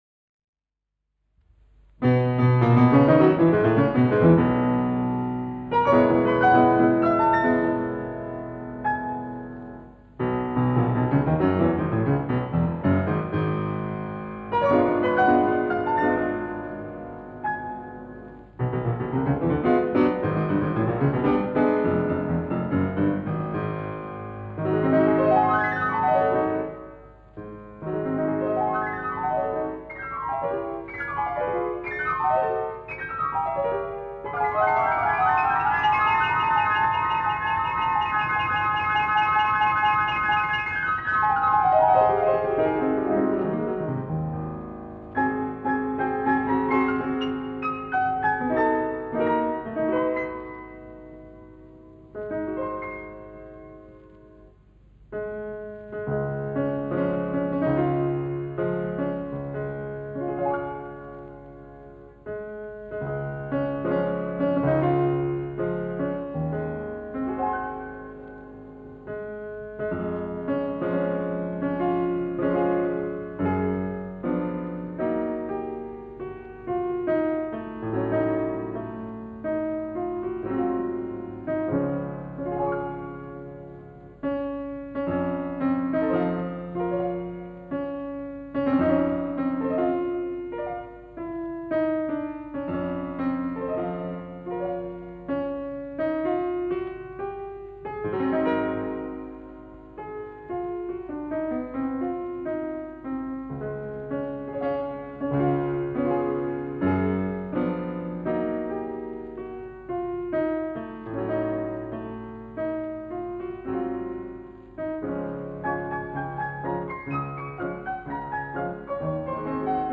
Аудио: Ф. Лист Концертный парафраз на тему из оперы Дж. Верди  «Риголетто», исп. А. Есипова (запись 1905 года)